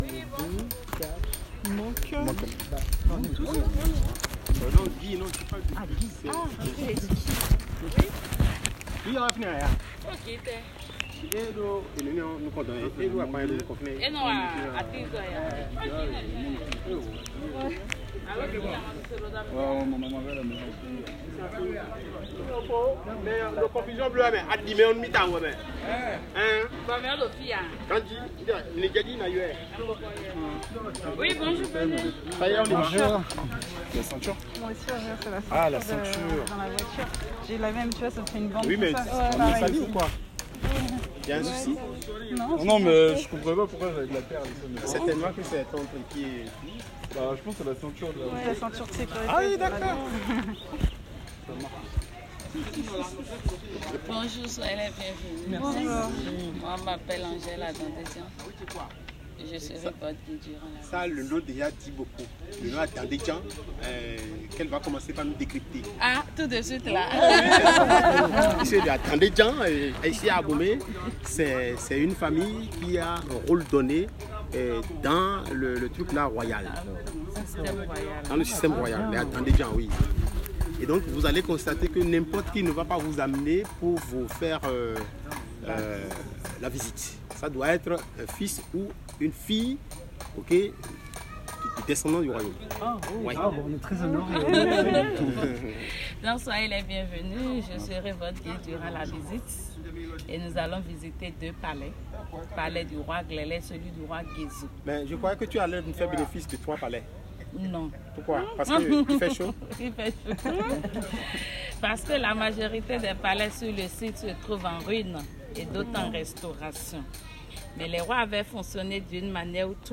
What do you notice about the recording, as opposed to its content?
Visite guidée musée d'Abomey